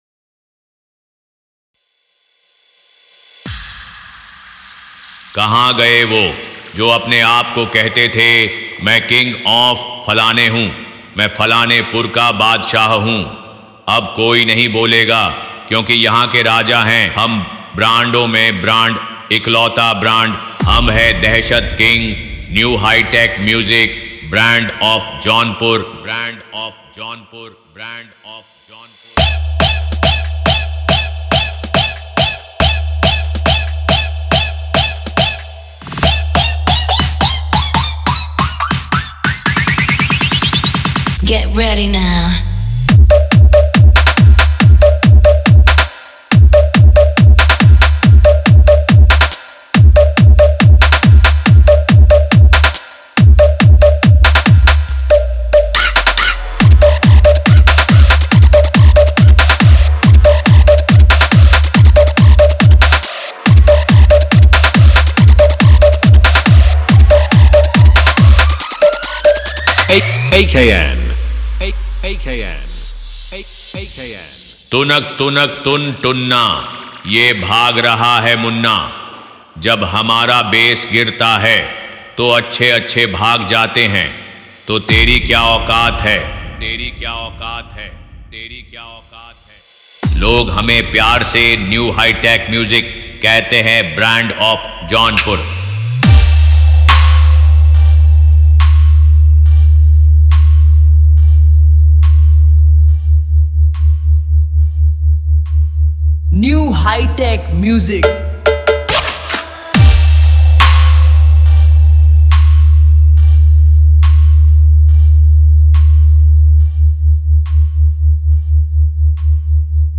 Stage Show DJ Mix
Bass Boosted DJ Remix, Party DJ Track